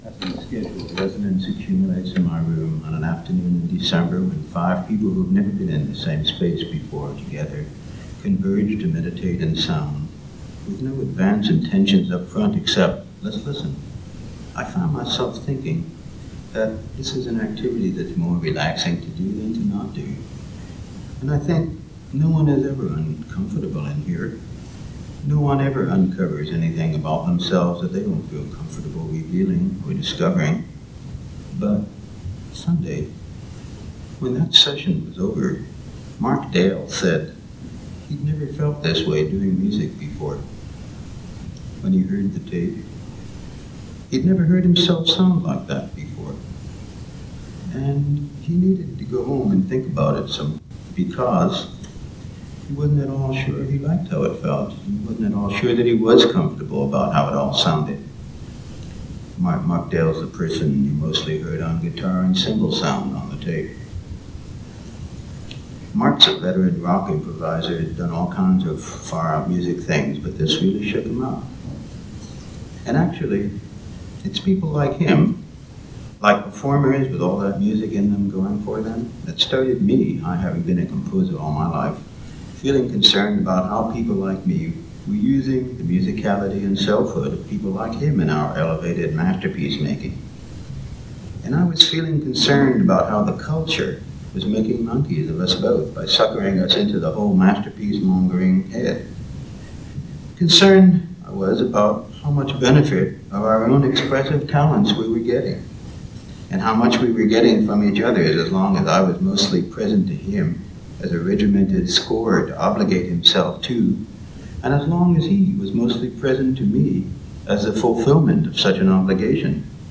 Introduction (a lecture at the University of Florida)